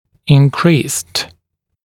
[ɪn’kriːst] [ин’кри:ст] повышенный, увеличенный; 2-я и 3-я форма от to increase (повышать, повышаться)